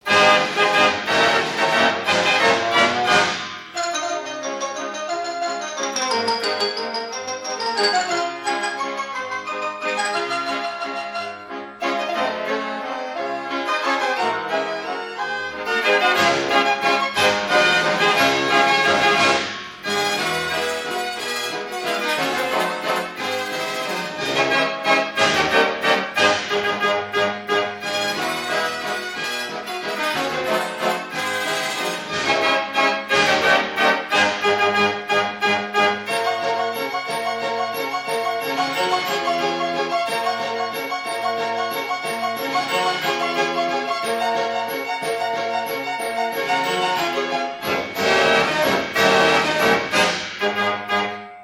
* HUPFELD HELIOS III/39 ORCHESTRION VOL. I, II, III & IV